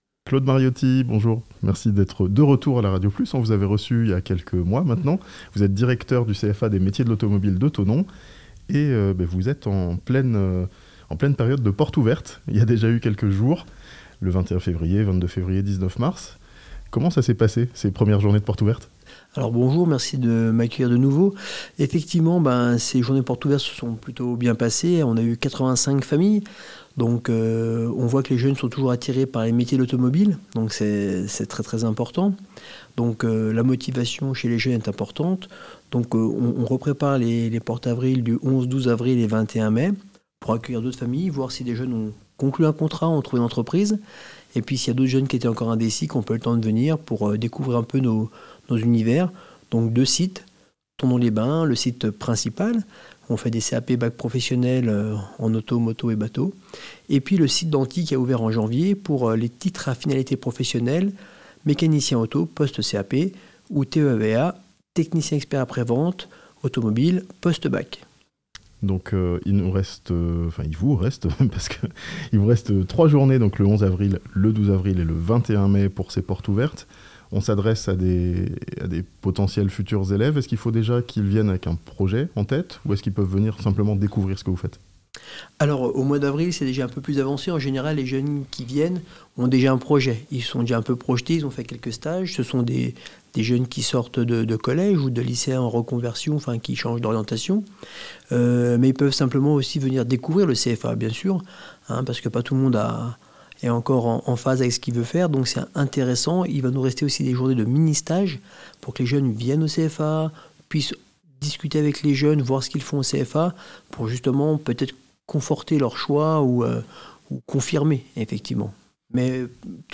Portes ouvertes au CFA des métiers de l'automobile de Thonon (interview)